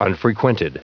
Prononciation du mot unfrequented en anglais (fichier audio)
Prononciation du mot : unfrequented